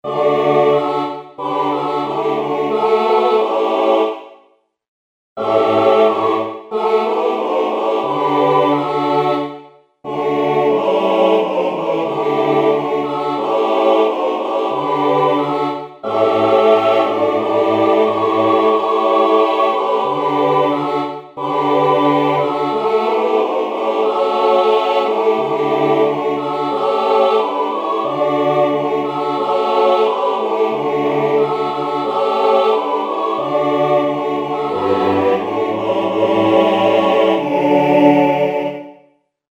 štirje glasovi
Z_Bogom_Marija_pravi_SATB_..mp3